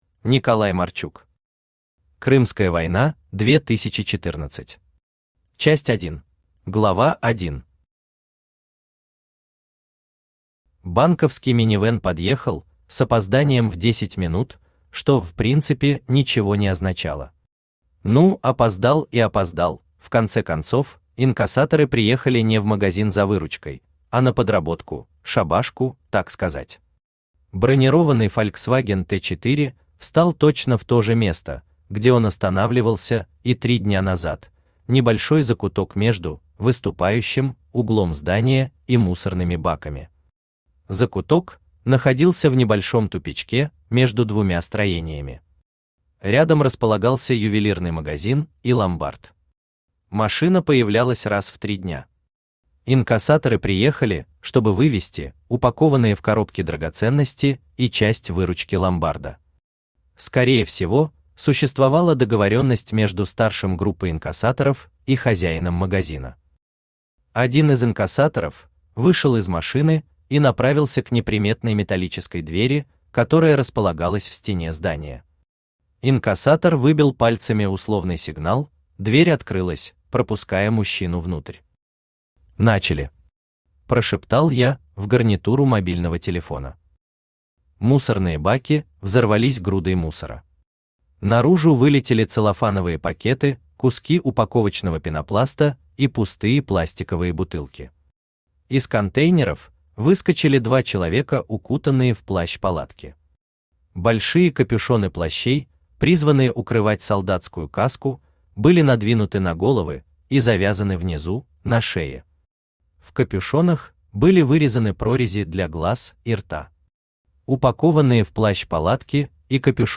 Аудиокнига Крымская война 2014. Часть 1 | Библиотека аудиокниг
Часть 1 Автор Николай Марчук Читает аудиокнигу Авточтец ЛитРес.